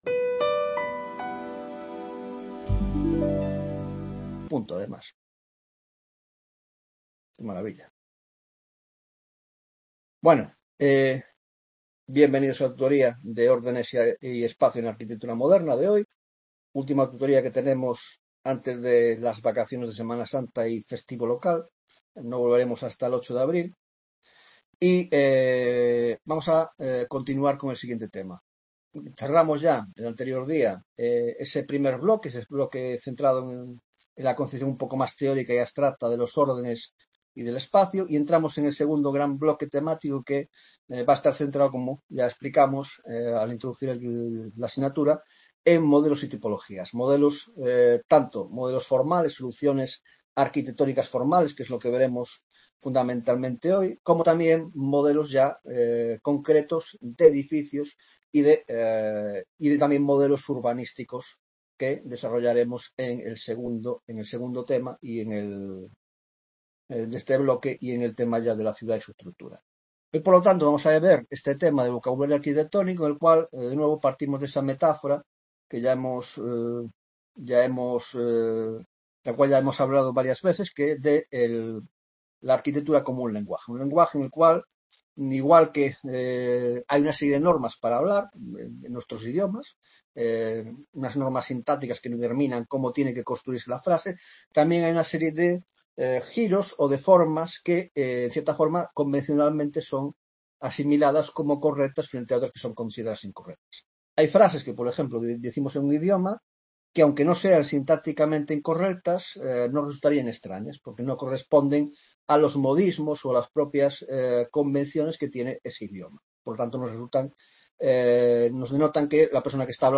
5ª Tutoría de Órdenes y Espacio en la Arquitectura Moderna (grado de Historia del Arte):1) El Lenguaje Arquitectónico (1ª parte), Aplicación de los órdenes: 1.1) Introducción general; 1.2) Problema de la combinación de orden y arco; 1.3) Superposición ortodoxa de órdenes y sus alternativas; 1.4) El Esquema de Arco del Triunfo.